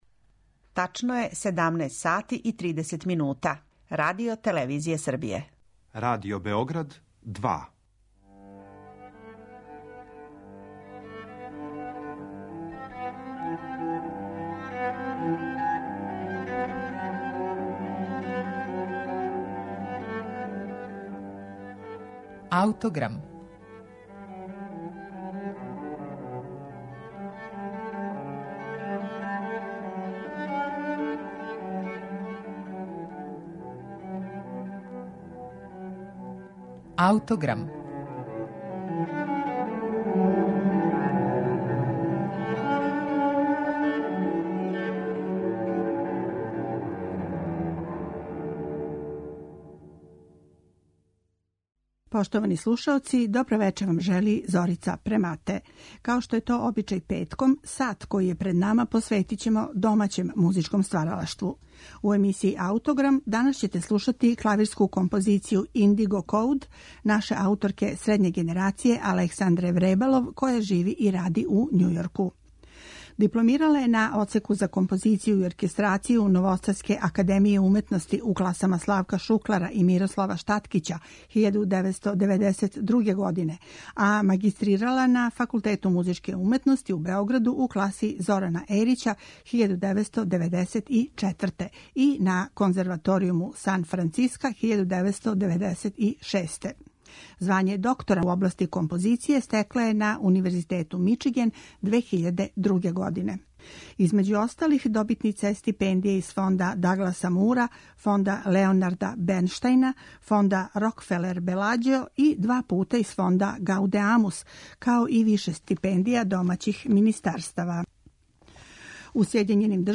клавирску композицију